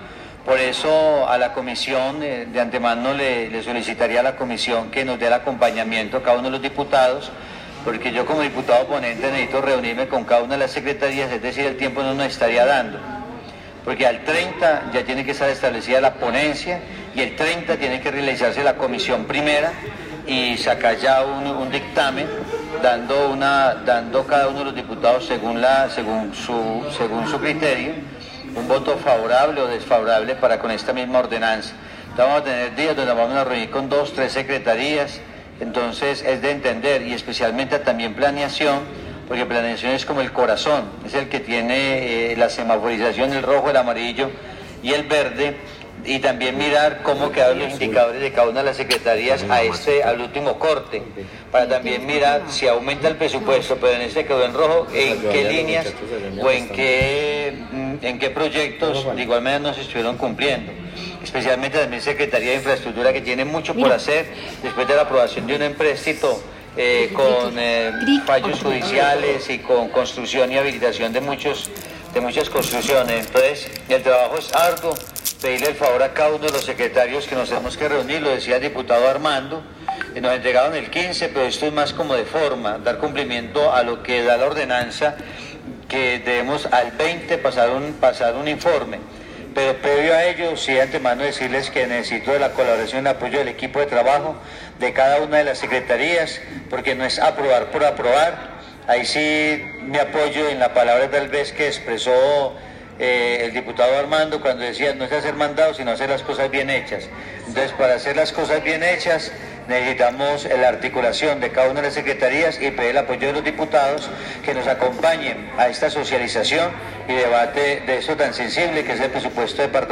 Audio de: Fabián Buriticá Gutiérrez, diputado ponente ante la comisión segunda
diputado-FABIAN-BURITICA.mp3